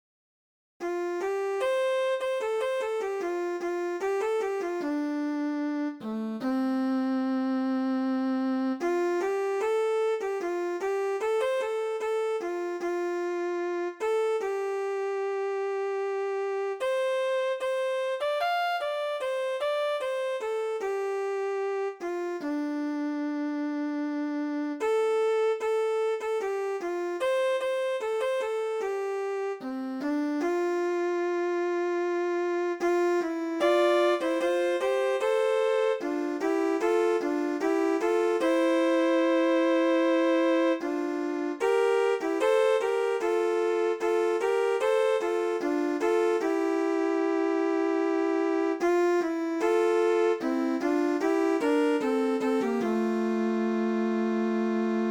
Thánh Ca Phụng Vụ